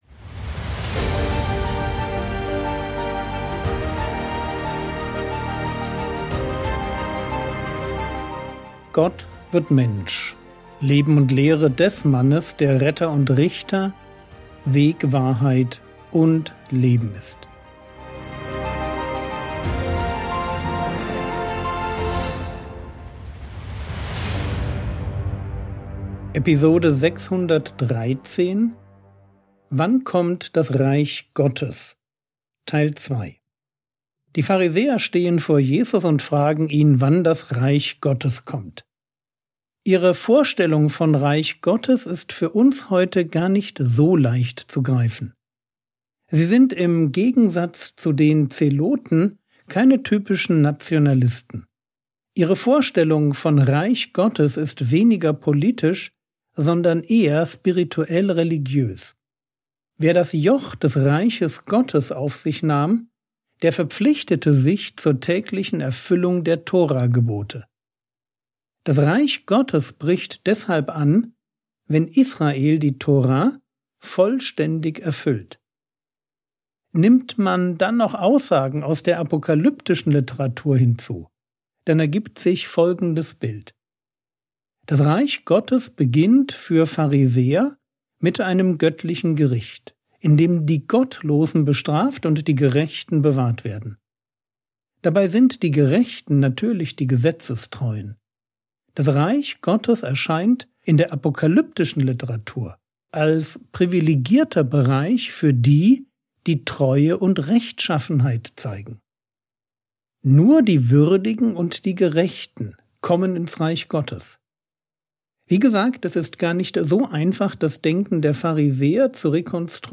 Episode 613 | Jesu Leben und Lehre ~ Frogwords Mini-Predigt Podcast